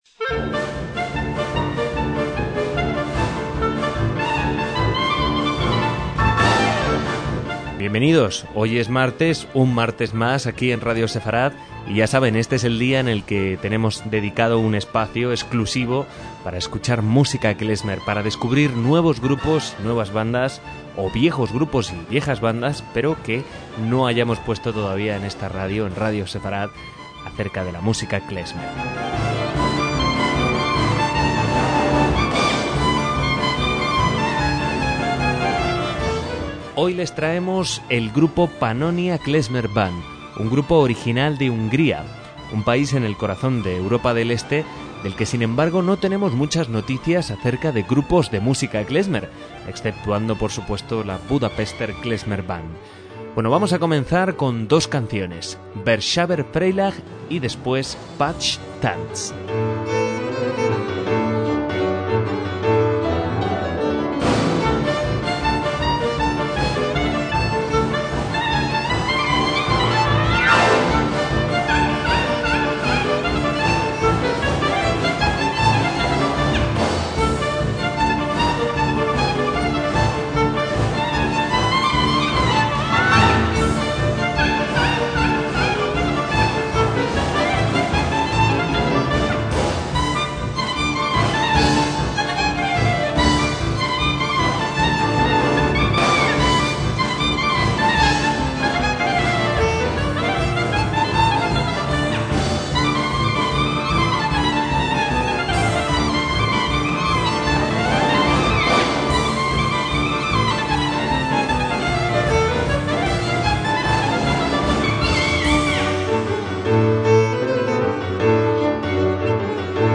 MÚSICA KLEZMER
violín
batería
clarinete
tuba
saxofón